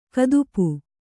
♪ kadupu